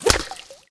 rifle_hit_liquid1.wav